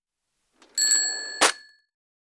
til-cash-register-mock-up
cash cash-register ding drawer foley ping ring slam sound effect free sound royalty free Sound Effects